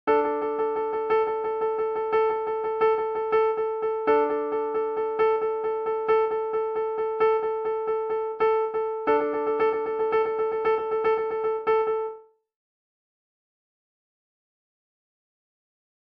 592. Dec Rats. 19 against 3.25, 4.33, 2.6, and 13